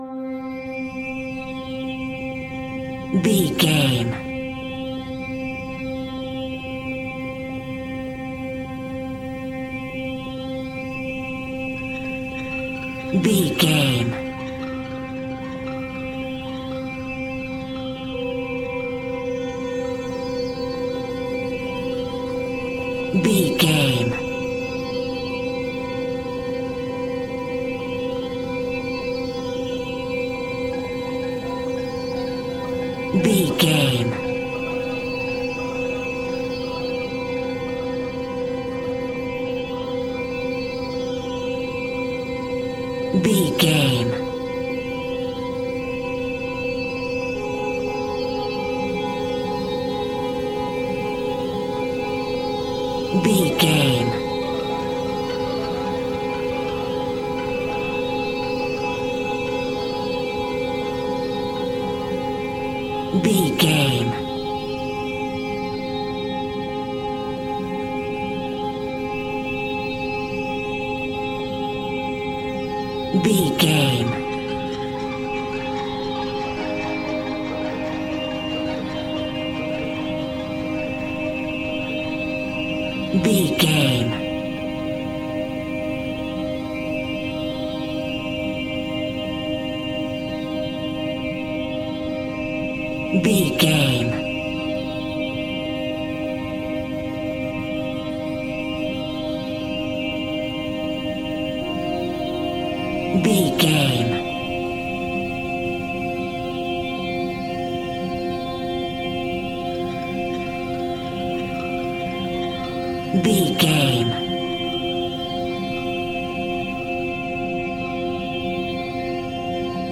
Aeolian/Minor
ominous
haunting
eerie
synthesizer
strings
Synth Pads
Synth Ambience